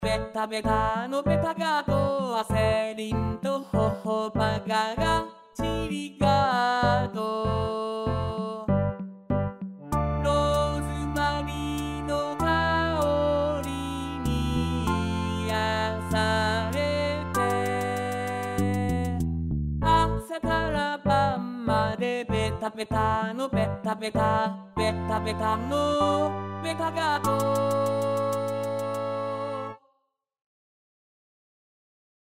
ベタベタのメロディが、ちょっと泣ける・・・ 「みじか！」
一度聴けば、メロディが頭から離れなくなる恐怖。